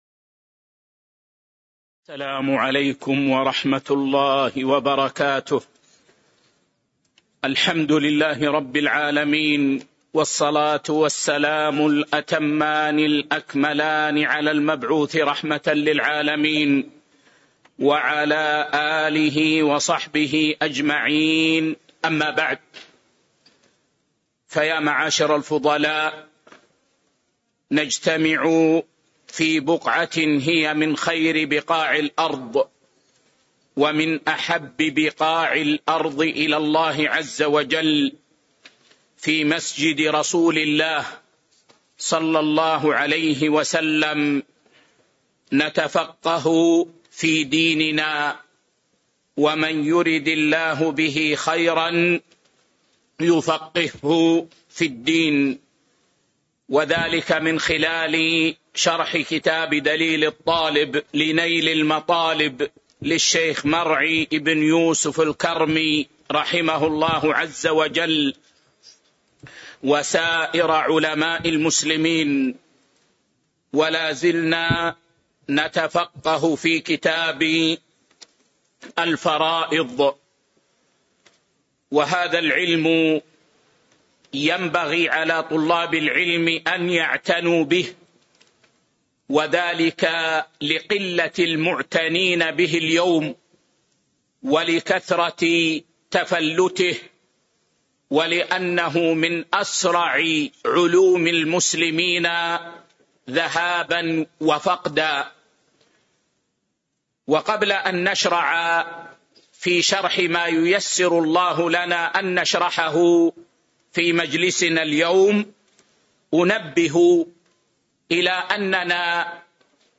شرح دليل الطالب لنيل المطالب الدرس 323 كتاب الفرائض [14] باب ميراث الحمل